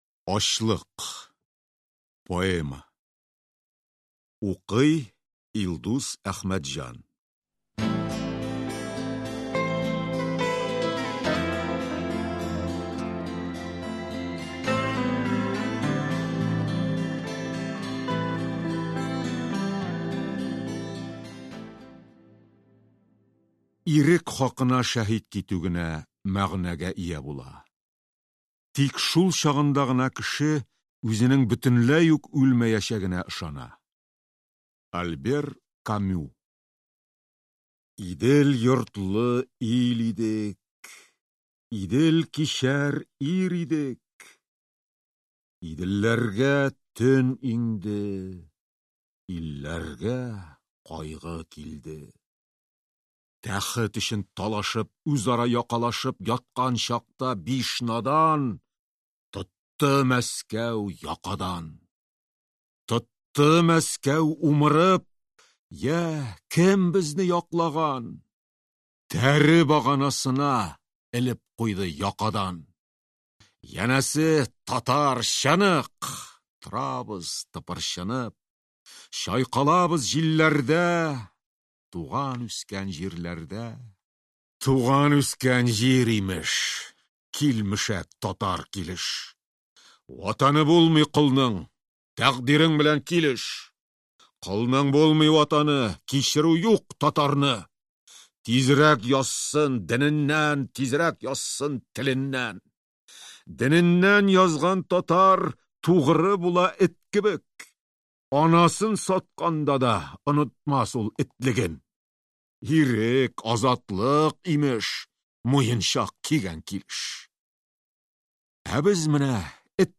Аудиокнига Ачлык | Библиотека аудиокниг
Прослушать и бесплатно скачать фрагмент аудиокниги